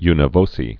(ynə vōsē, nə vōkā)